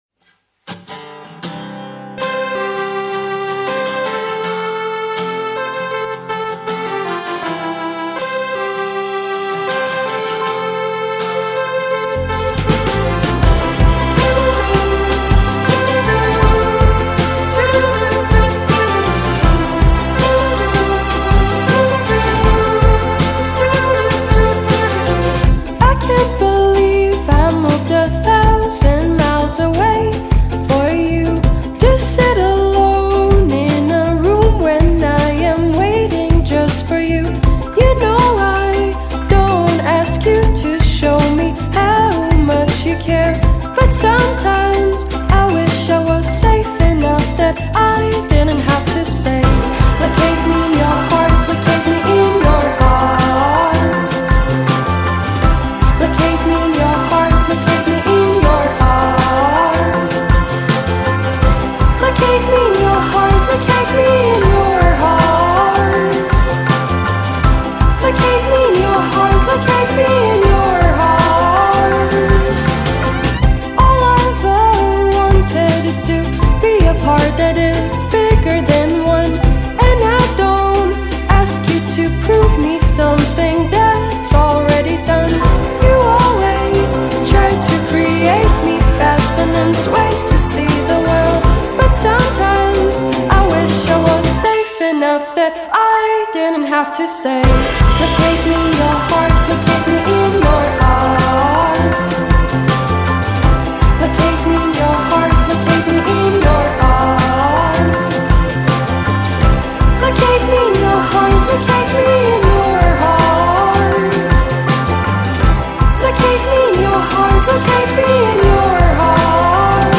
indie-pop influenced singer-songwriter